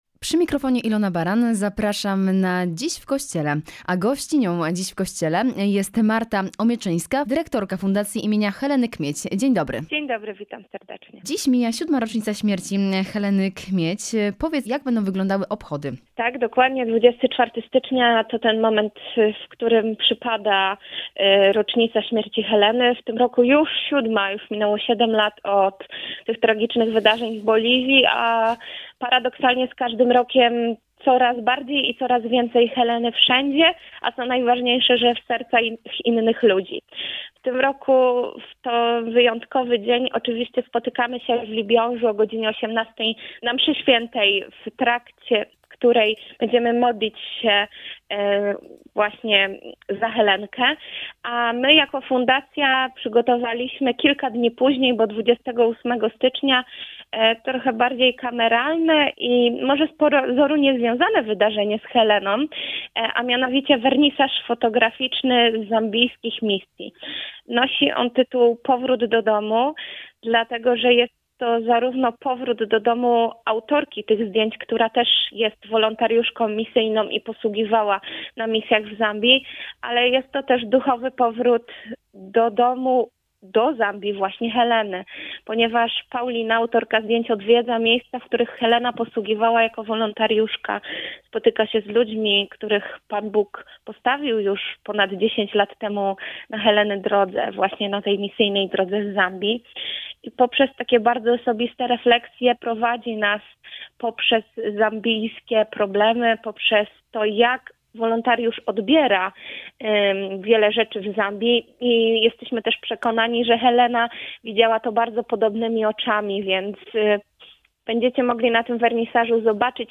Łączymy się telefonicznie